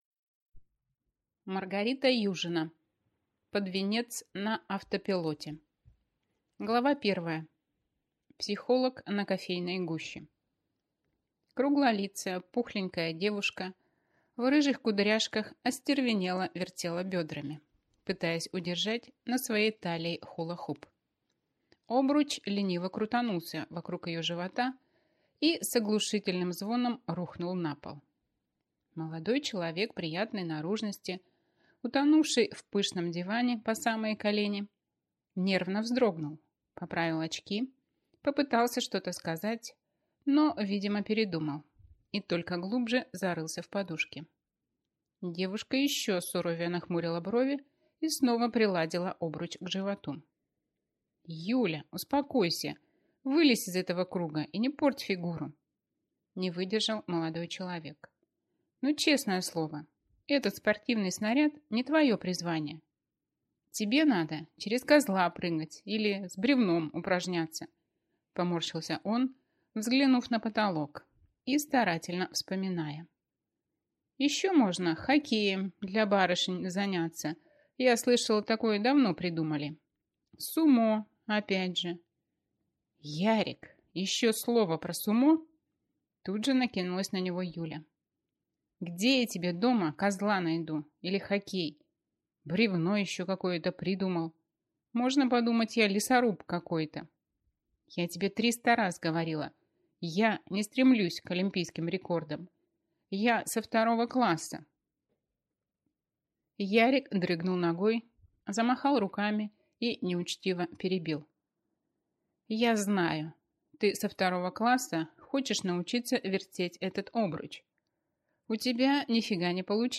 Аудиокнига Под венец на автопилоте | Библиотека аудиокниг
Прослушать и бесплатно скачать фрагмент аудиокниги